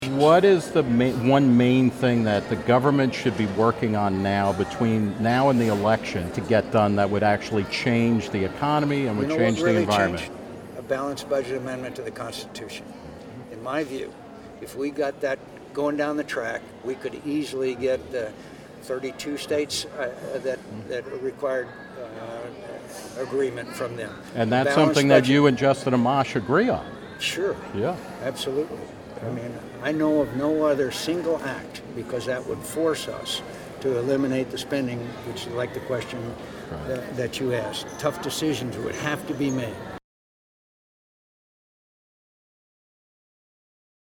Today, outside the Values Voters Summit in Washington, D.C., Reason TV caught up with Sen. John McCain (R-Az.) where he talked about his strong support for a balanced budget amendment to the Constitution.